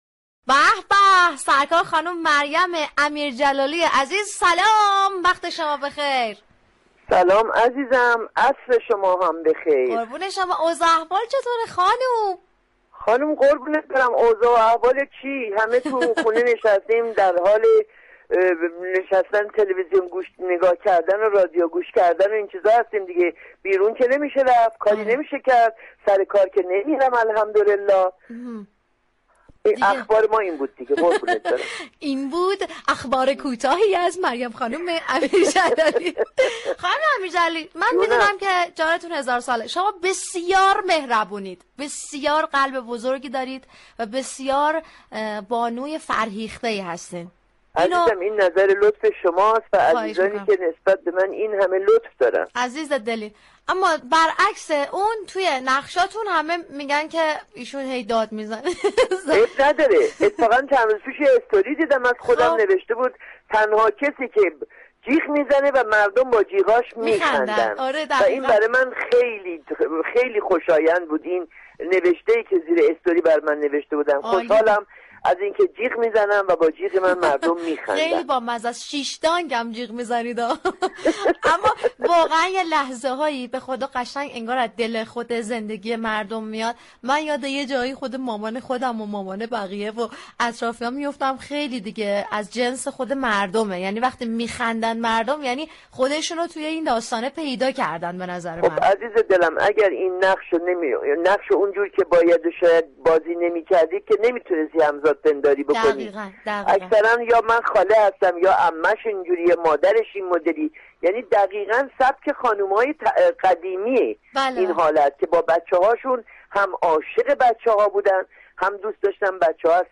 مریم امیر جلالی بازیگر طنز در گفتگو با «رادیو صبا» در باره بازیگری طنز صحبت كرد.